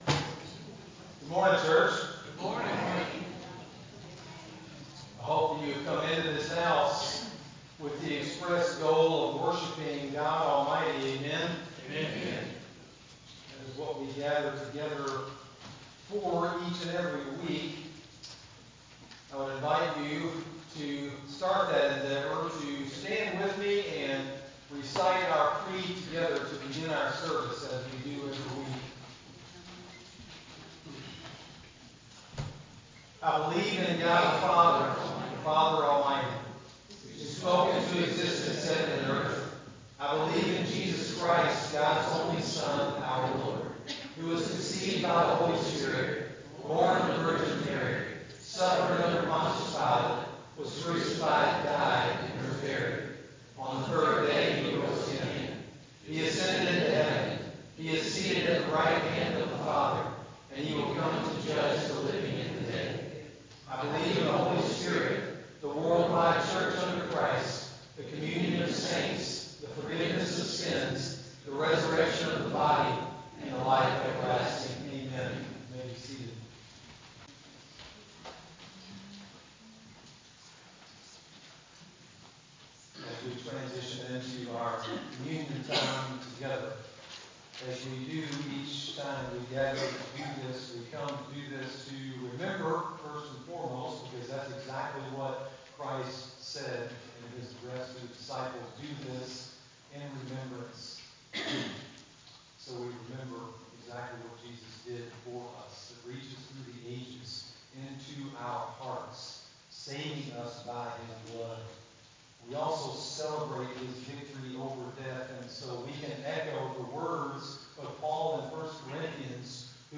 Sermon-9-1-24-CD.mp3